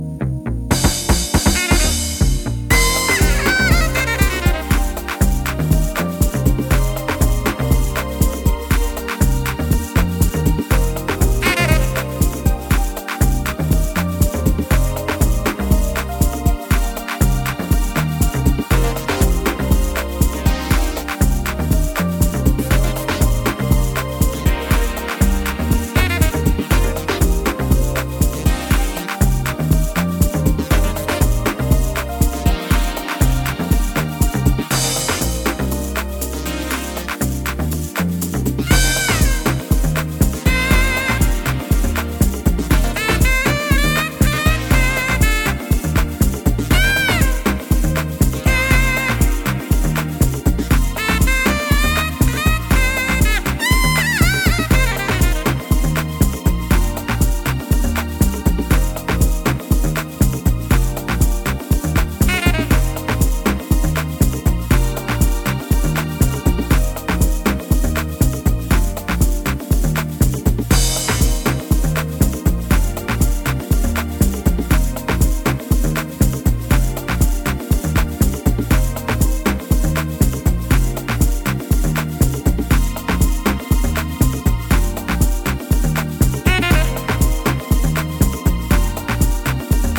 ジャンル(スタイル) DEEP HOUSE / DISCO